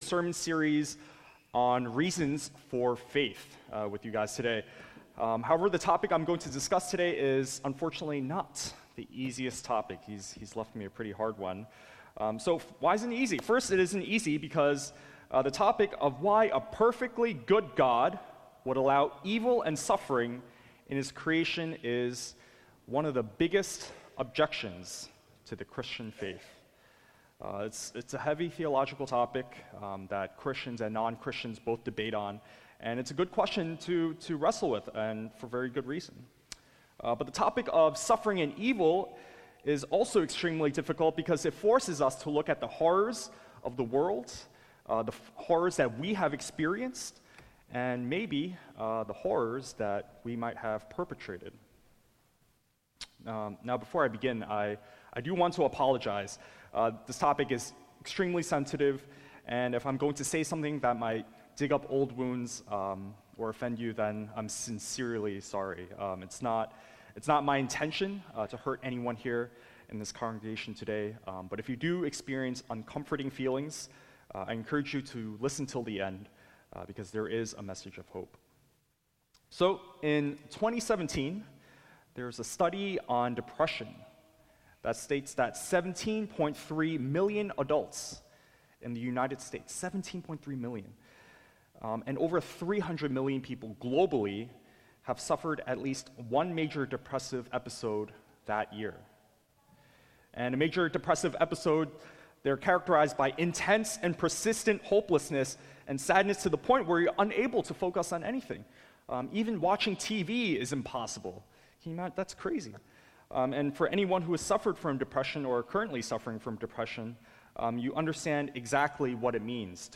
"Heaven and Hell" The sixth message in our "Reasons for Faith" sermon series, looking at the reasons to believe in the Christian understanding of heaven and hell. Sermon text is Luke 16:19-31.